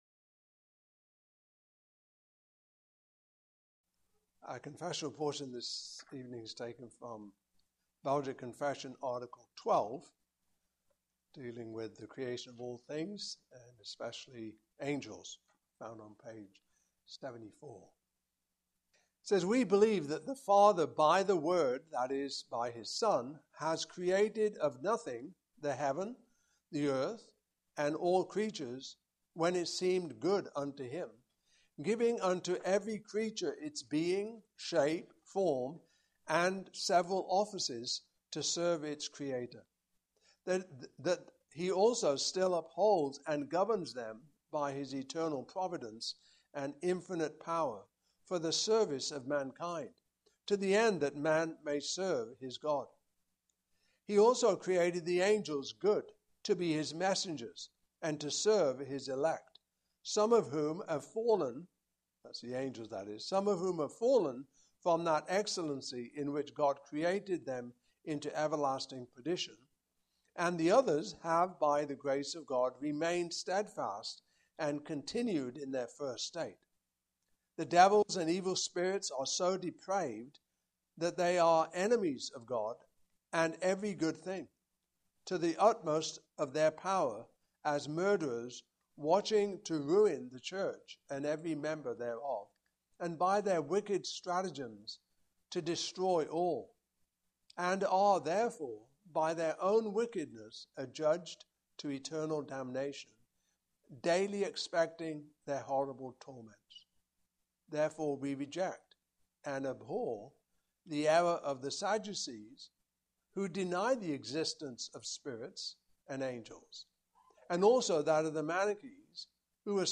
Passage: 2 Peter 2:1-22 Service Type: Evening Service Topics